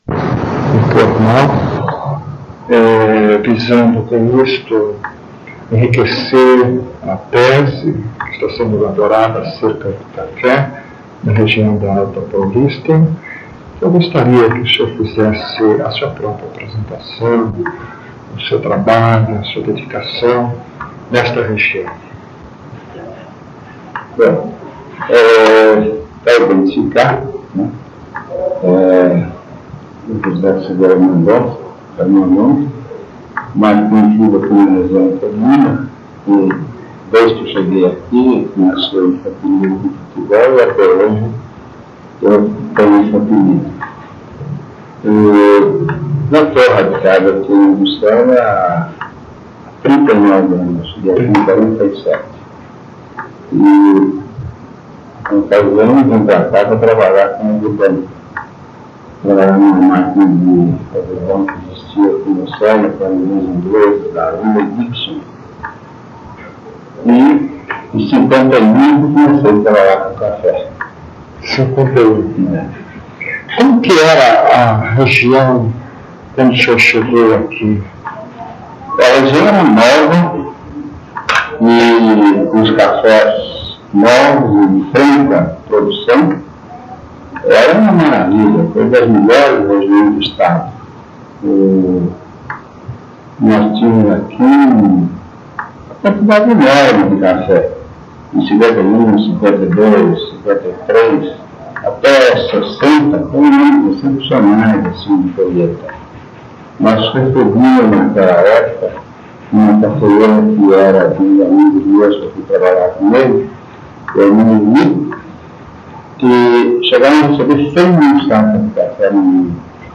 Entrevista
*Recomendado ouvir utilizando fones de ouvido.